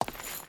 Stone Chain Walk 1.wav